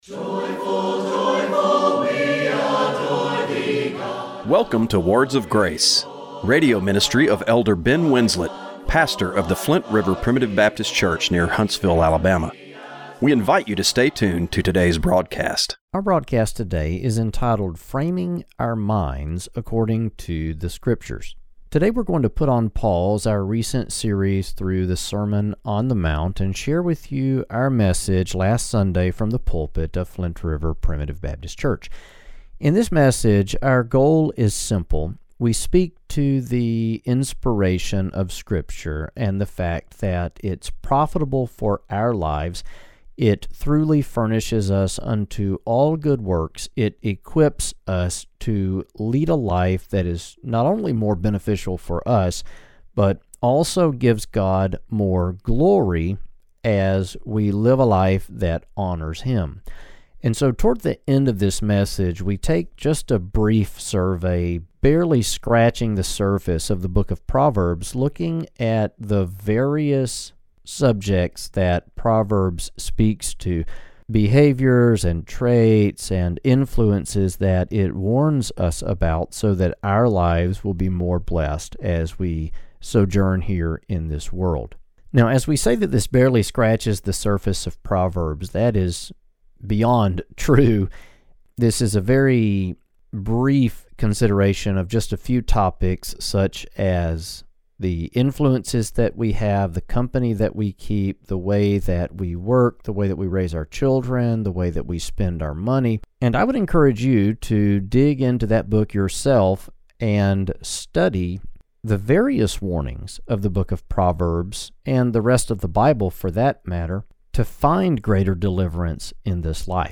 Radio broadcast for March 30, 2025.